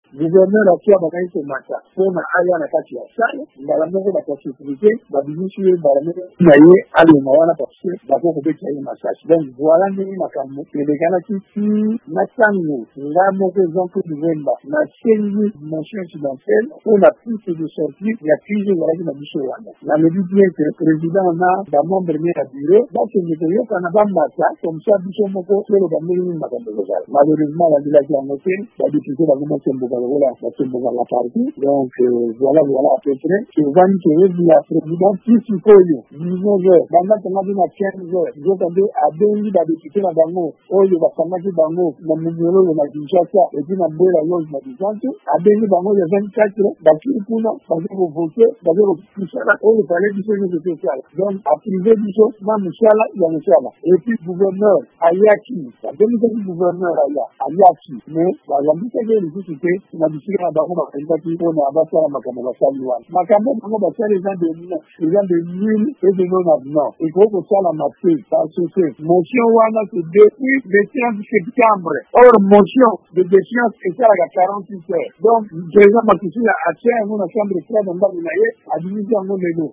VOA Lingala ebengaki député Jean-Claude Mvuemba.